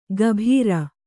♪ gabhīra